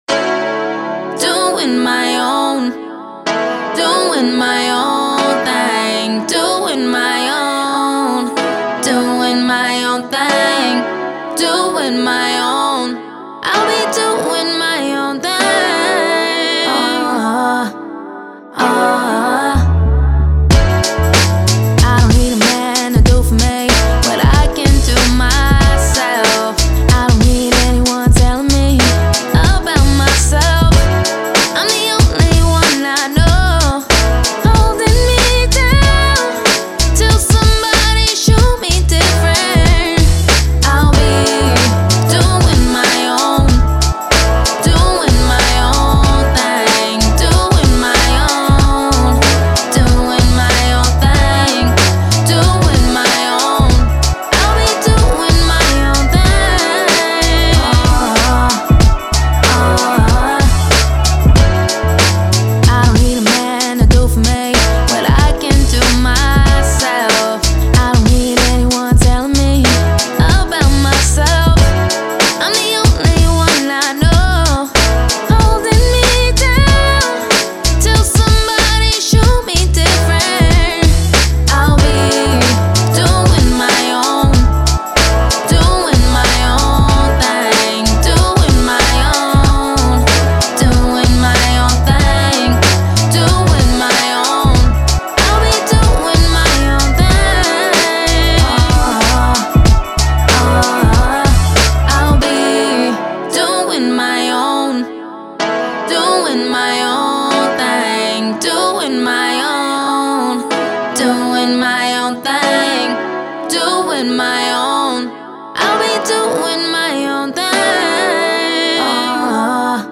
90s, R&B
F# Minor